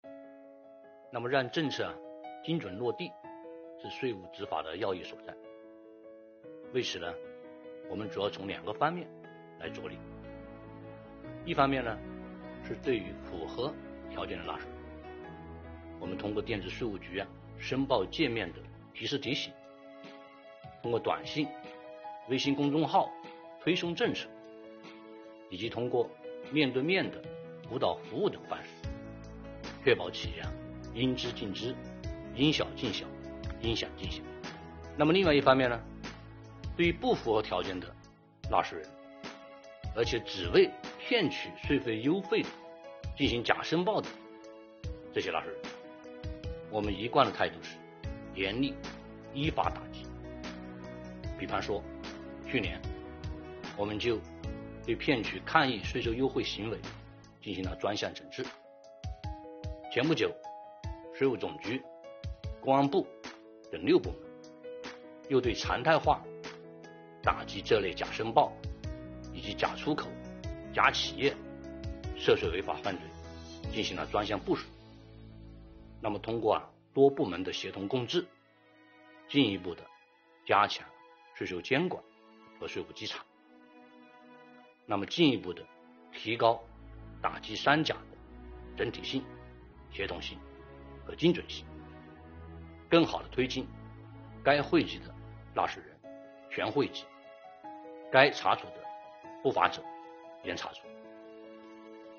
近日，国务院新闻办公室举行国务院政策例行吹风会，国家税务总局相关负责人介绍制造业中小微企业缓税政策等有关情况，并答记者问。